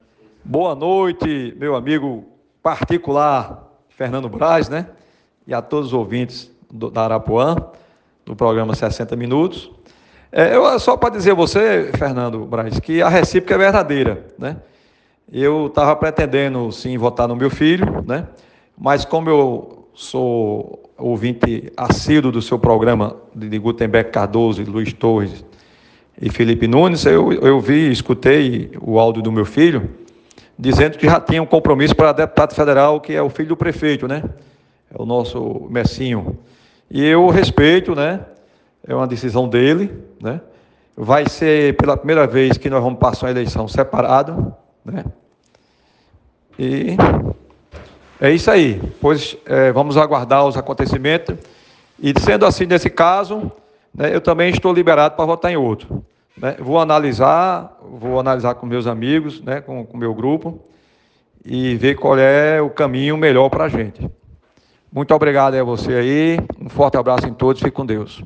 Já o pré-candidato a deputado federal e vereador, Mikika Leitão, após ouvir a declaração do filho disse que a recíproca é a mesma e que irá seguir a campanha sem o apoio do filho. Com voz embargada, o presidente do MDB de João Pessoa disse que se sente ‘liberado’ para escolher outro pré-candidato a deputado estadual.
Ouça a declaração do vereador Mikika Leitão: